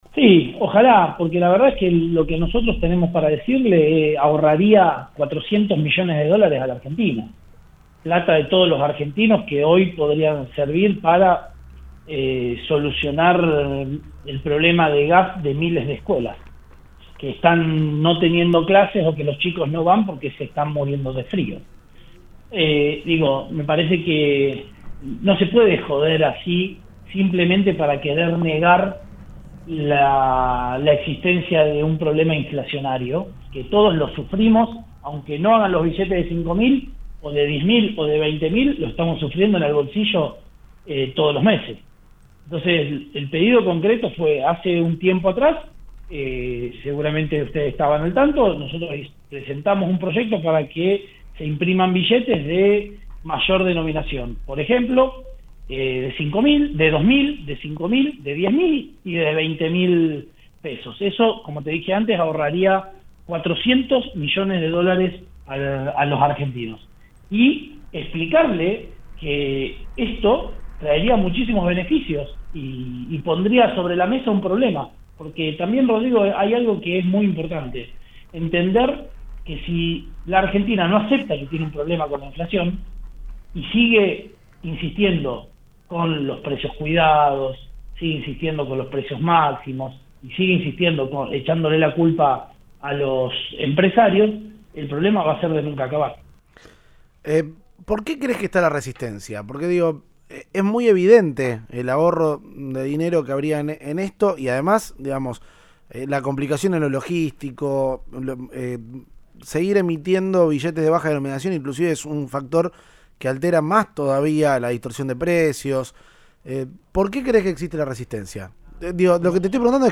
El diputado nacional del PRO habló en Cadena 3 Rosario tras pedir una audiencia con el titular del Banco Central, por un proyecto monetario que busca achicar gastos ante la incesante inflación.